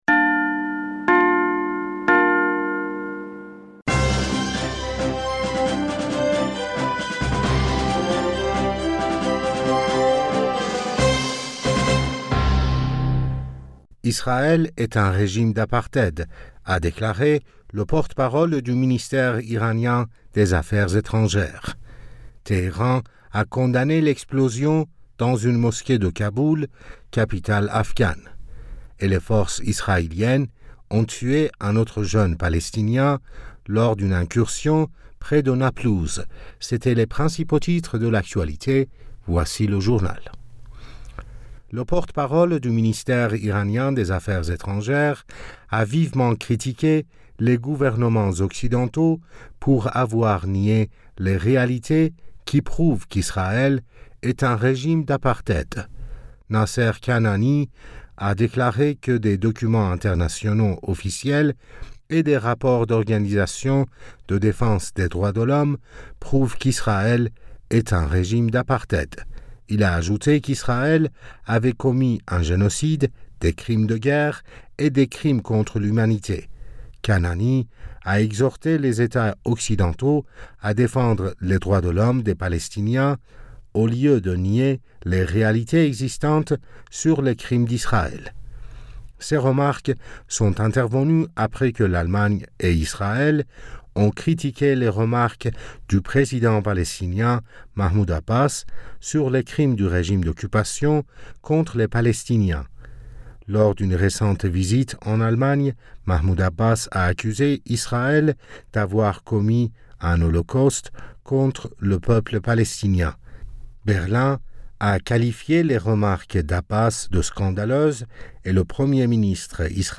Bulletin d'information Du 18 Aoùt